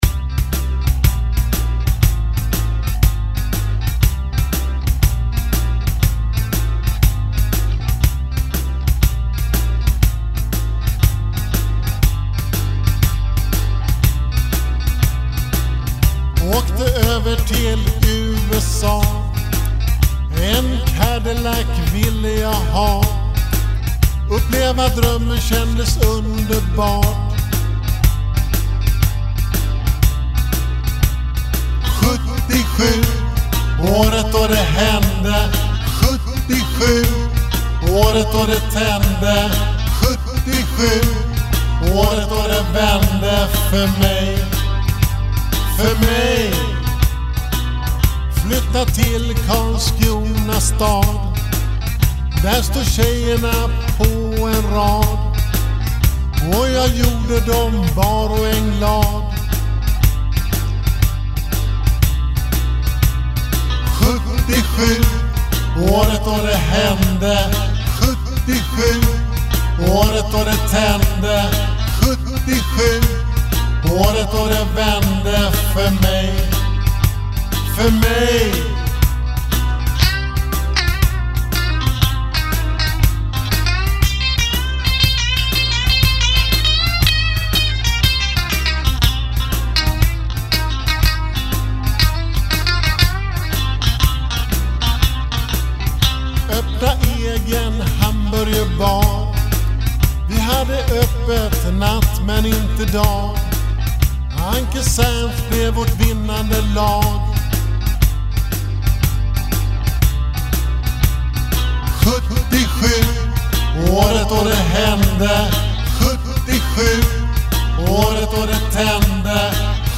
gitarrsolo